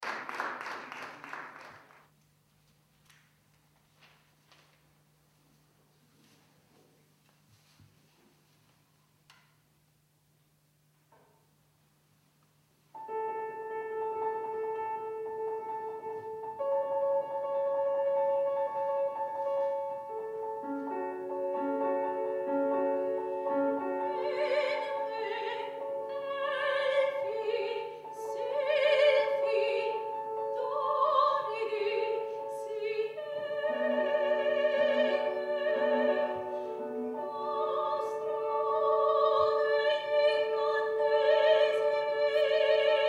17 ottobre 2009 - II OTTOBRE MUSICALE A PALAZZO VALPERGA - Concerto - Arie Italiane